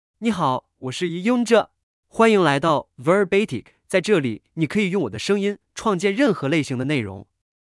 Yunjie — Male Chinese (Mandarin, Simplified) AI Voice | TTS, Voice Cloning & Video | Verbatik AI
MaleChinese (Mandarin, Simplified)
Yunjie is a male AI voice for Chinese (Mandarin, Simplified).
Voice sample
Listen to Yunjie's male Chinese voice.
Yunjie delivers clear pronunciation with authentic Mandarin, Simplified Chinese intonation, making your content sound professionally produced.